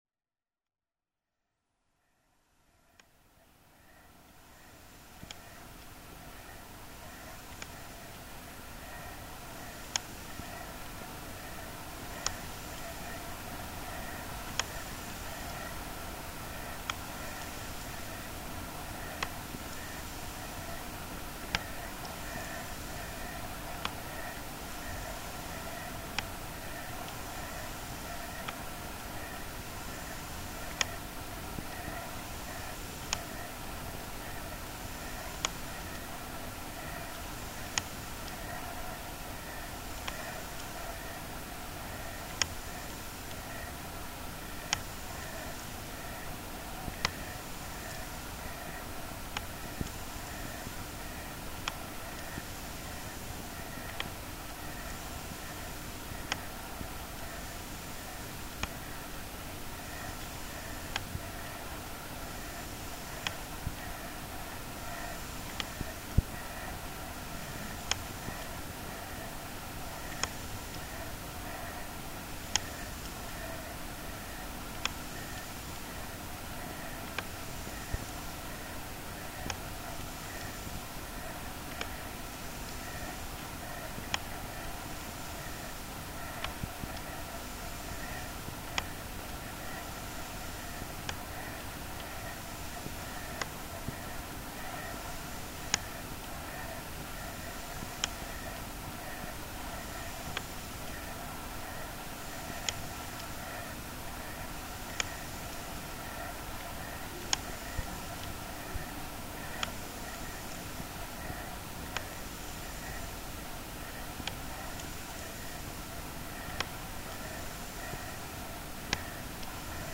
acoustic incidents of a room